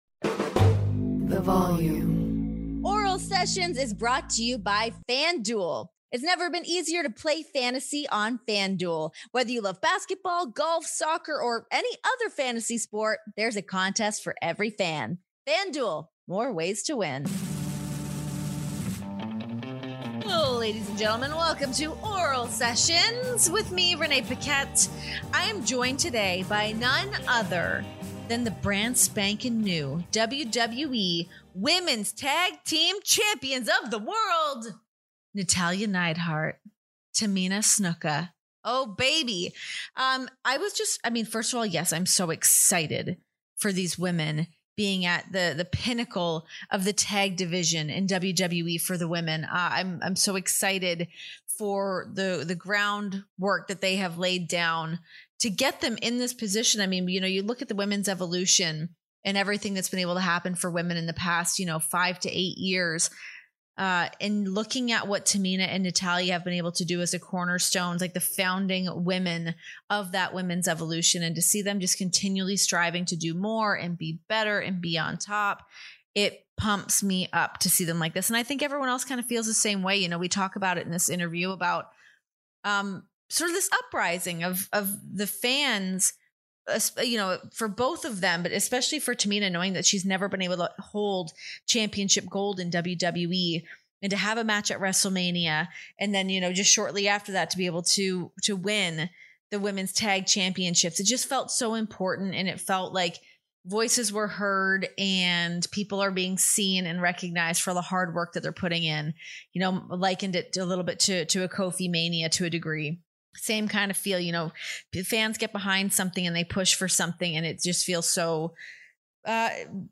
Fresh off of main-eventing Monday Night Raw, WWE Women’s Tag Team Champions Natalya & Tamina join Oral Sessions to talk Tamina’s 4,000-day path to her first women’s title; Vince McMahon’s reaction to the big win; and an extended praise for Tyson Kidd, a.k.a. Brudda TJ. Also, everyone cries a lot.